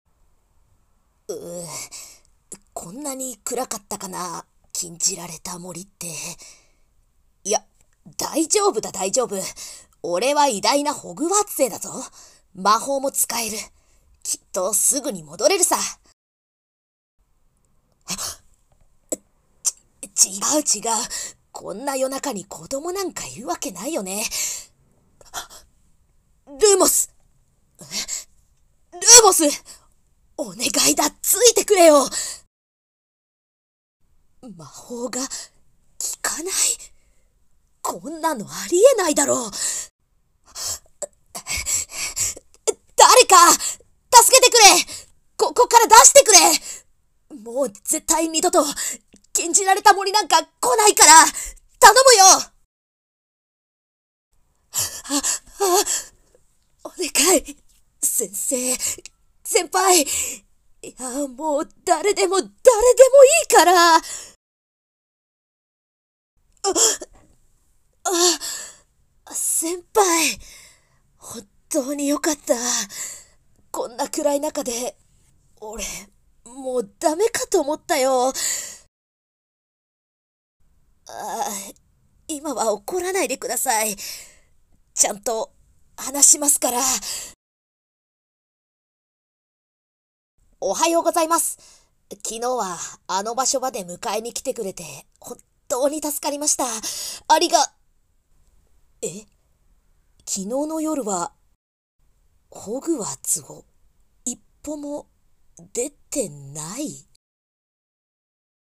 】応募声劇 🐦‍🔥 nanaRepeat